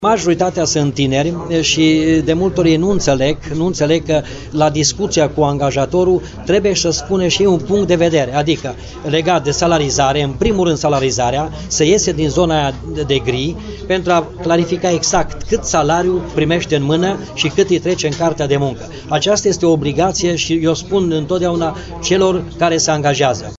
Peste 1.600 de sesizări au fost depuse anul trecut la Inspectoratul Teritorial de Muncă, filiala Timiş, majoritatea fiind legate de neplata salariilor sau a sporurilor. O mare parte din sesizări au fost depuse de tineri, spune directorul ITM Timiş, Pavel Kaşai.